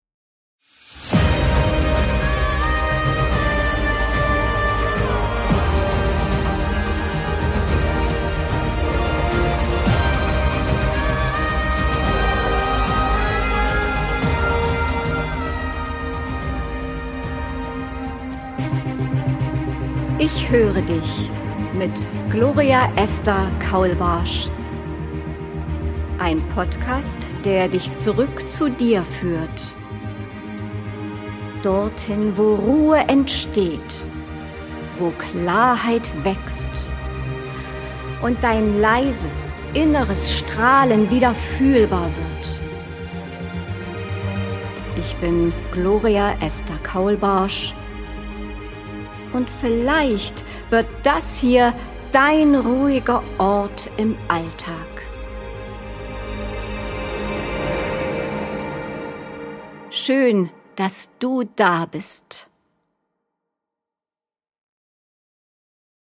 Trailer - ein leiser Einstieg in "Ich höre dich"
Ein Geschichten-Podcast aus dem echten Leben.